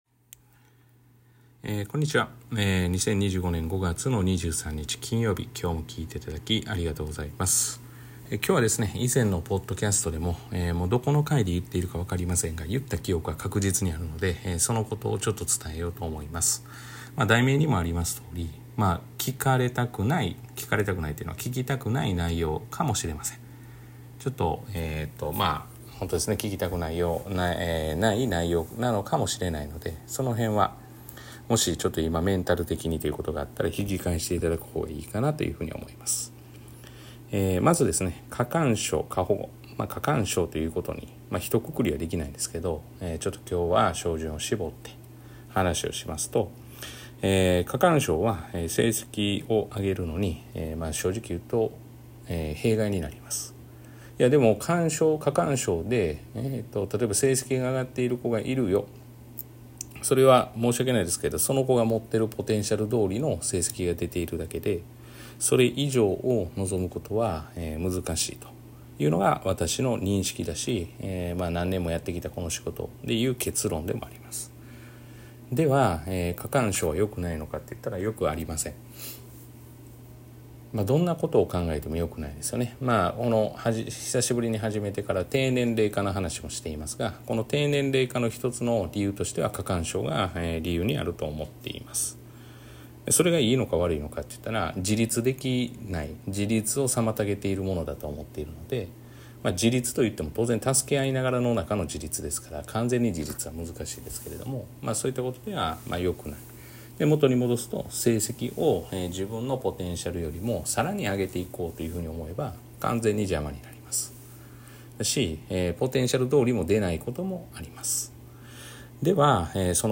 「塾に頼らず勉強できるようにする！」を本気で実践している塾講師が日常で起きる出来事を「ゆるーく」話します。